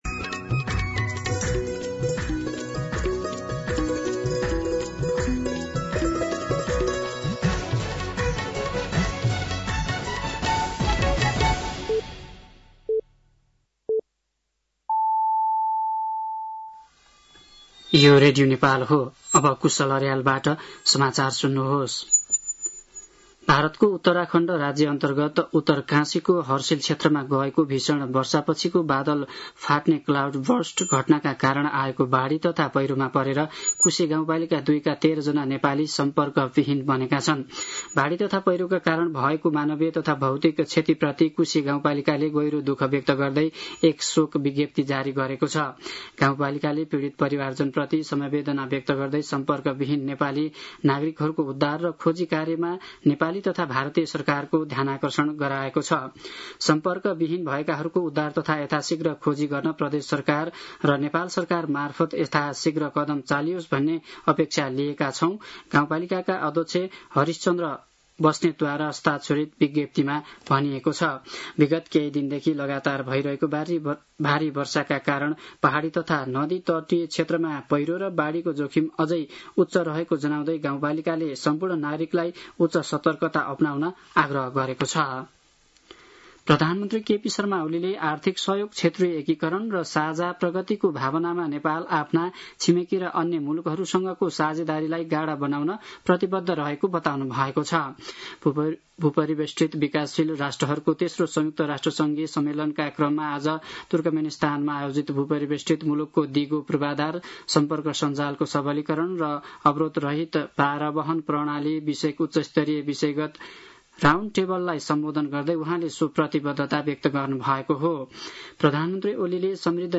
साँझ ५ बजेको नेपाली समाचार : २२ साउन , २०८२
5.-pm-nepali-news-1-2.mp3